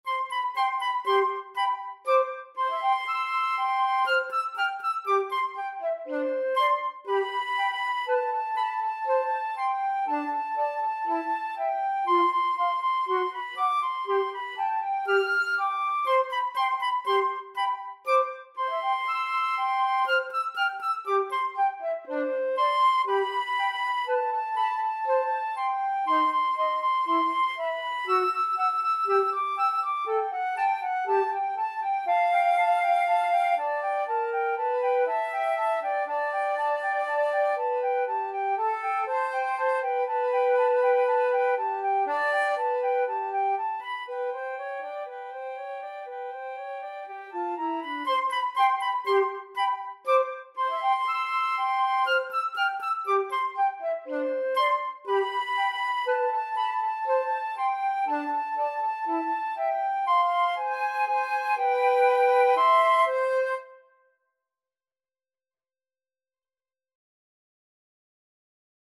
Free Sheet music for Flute Duet
Flute 1Flute 2
C major (Sounding Pitch) (View more C major Music for Flute Duet )
Allegro (View more music marked Allegro)
4/4 (View more 4/4 Music)
Classical (View more Classical Flute Duet Music)